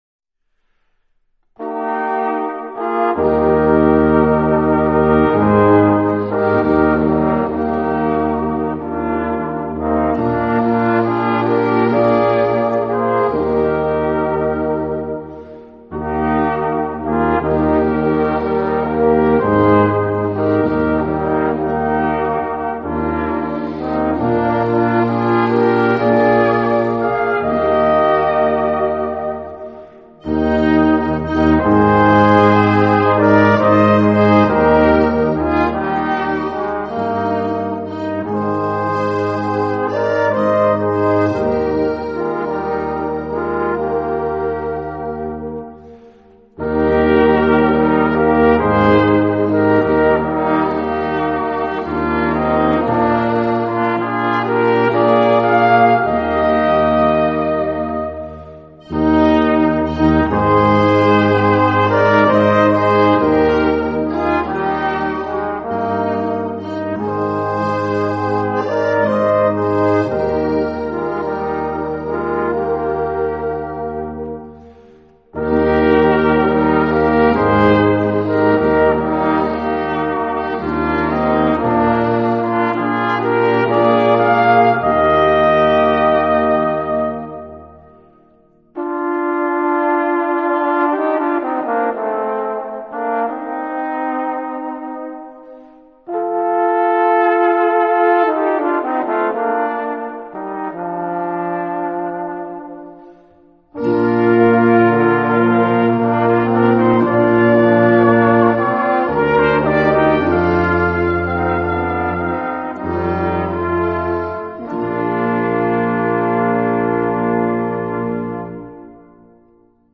Besetzung: Ensemblemusik für 4 Blechbläser
1. und 2. Flügelhorn B
Posaune B und C
Tuba in C, B und Es